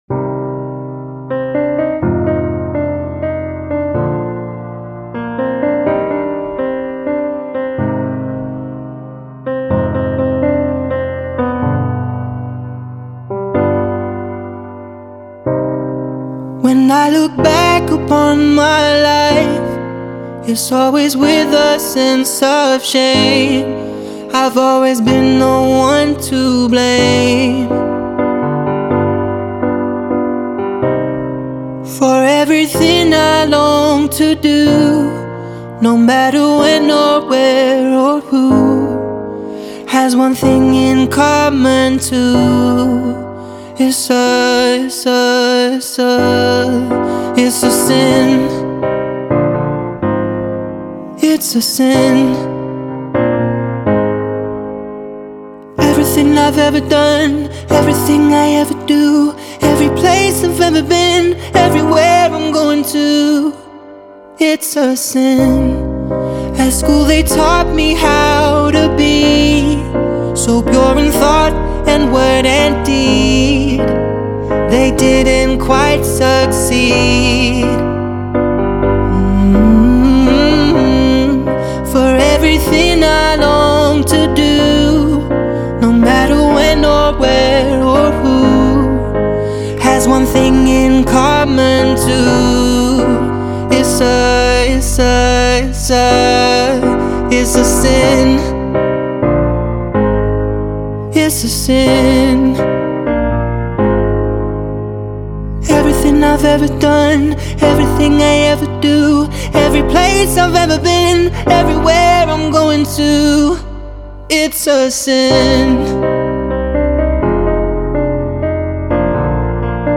поп-песня